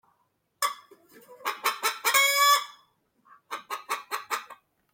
Clocló, clocló
GALLINA (audio/mpeg)